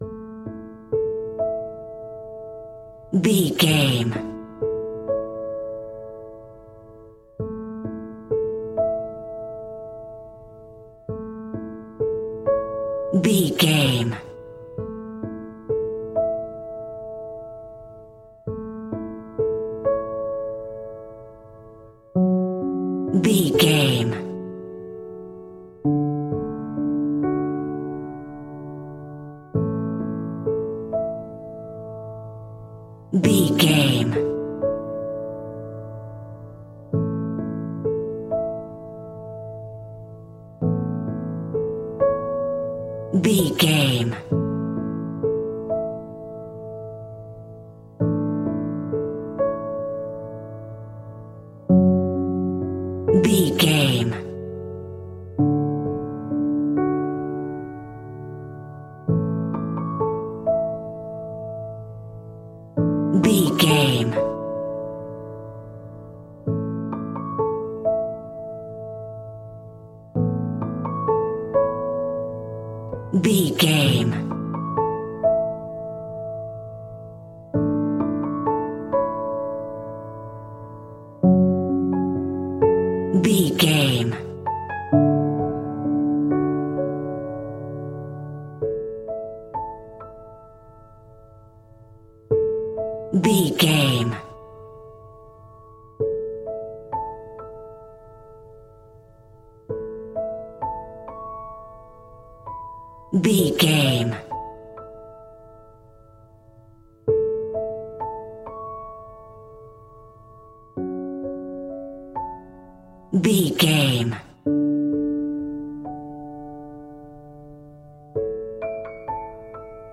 Peaceful and chilled piano music.
Regal and romantic, a classy piece of classical music.
Ionian/Major
soft